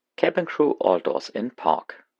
DisarmDoors.ogg